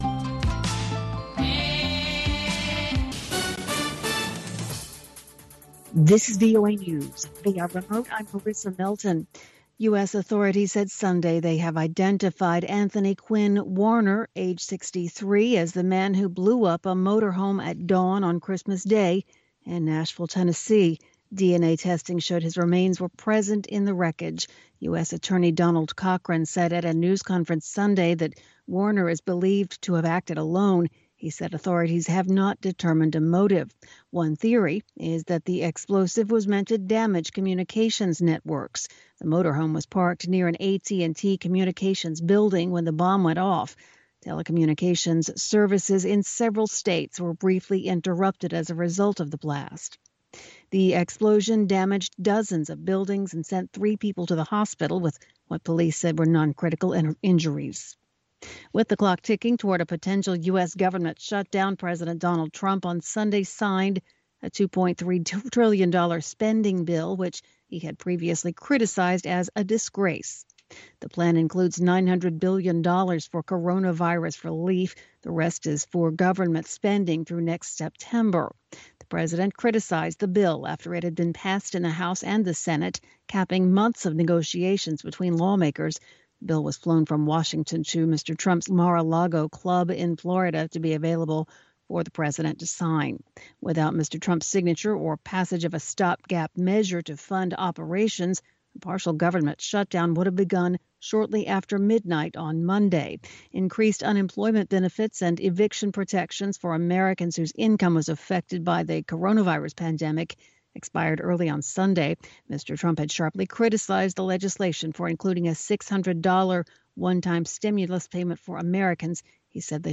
African Beat showcases the latest and the greatest of contemporary African music and conversation. From Benga to Juju, Hip Life to Bongo Flava, Bubu to Soukous and more